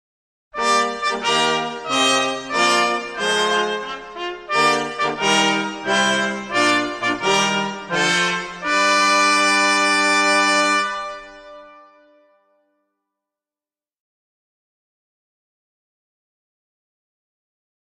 fanfara15.mp3